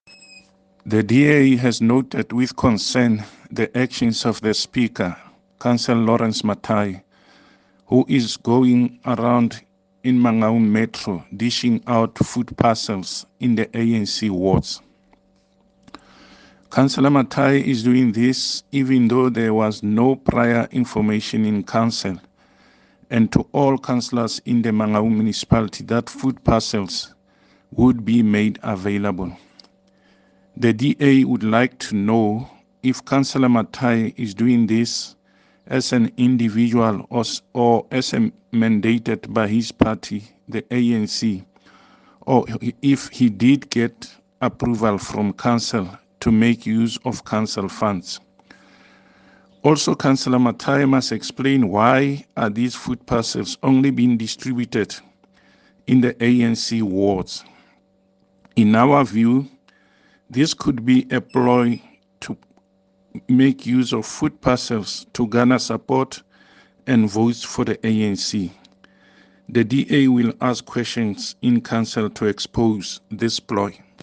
English and Sesotho soundbites by Cllr David Masoeu and